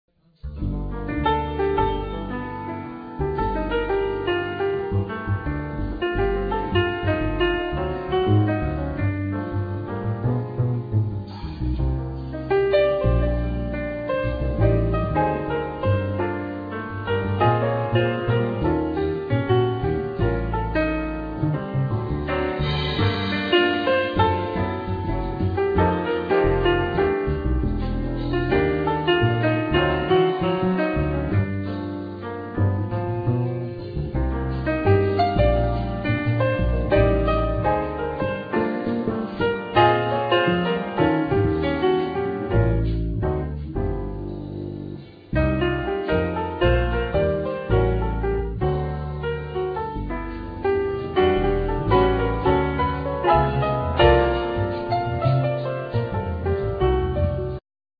Piano
Double bass
Drums
Ac guitar
Bandoneon